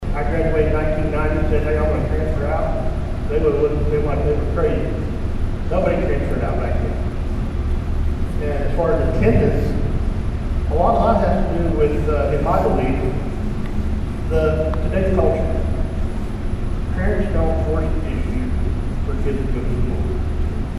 Two candidates for the Nowata Board of Education appeared at a forum on Monday night in the district administration building.